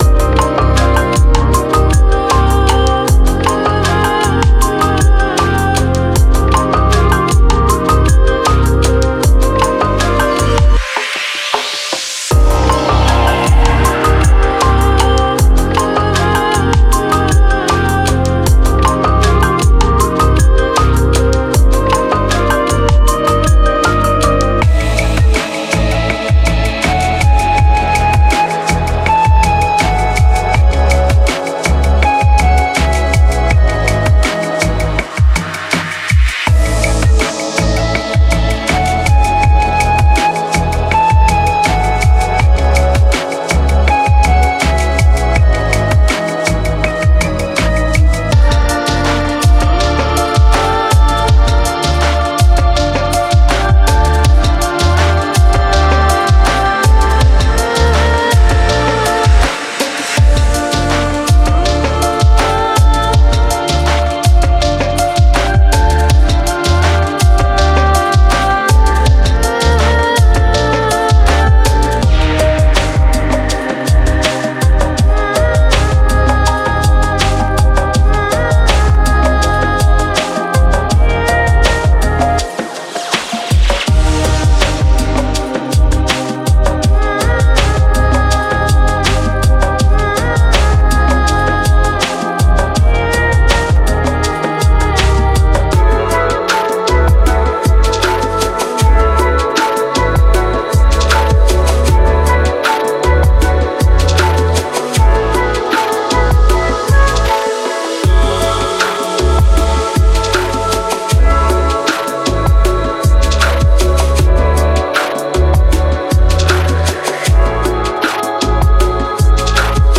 Genre:Chillout